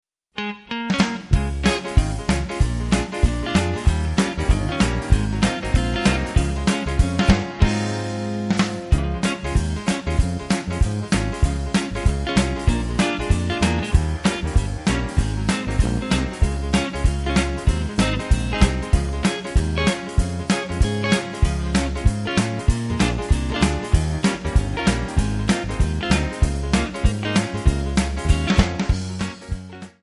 Bb
MPEG 1 Layer 3 (Stereo)
Backing track Karaoke
Pop, Oldies, Jazz/Big Band, 1950s